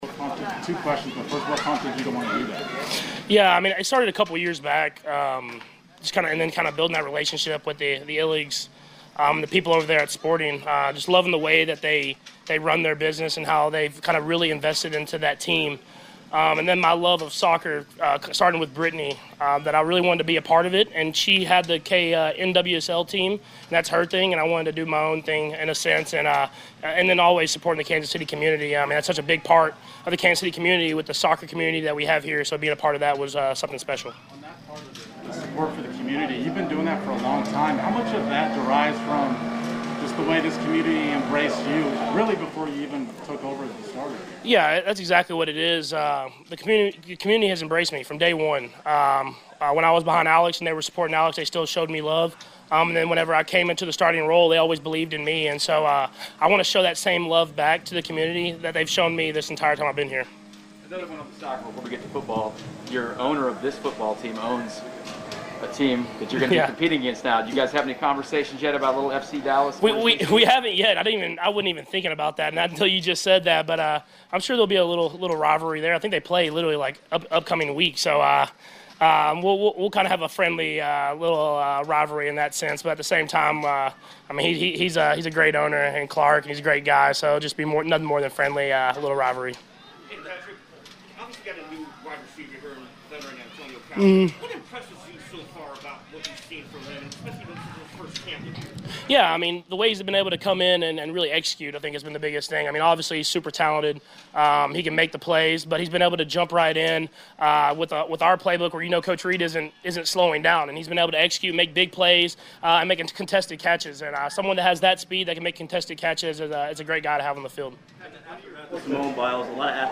Patrick Mahomes visits with the media after Fridays practice.